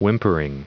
Prononciation du mot whimpering en anglais (fichier audio)
Prononciation du mot : whimpering